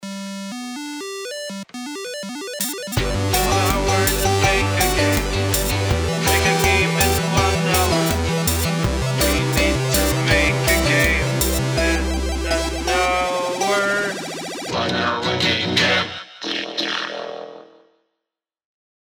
A chiptune rendition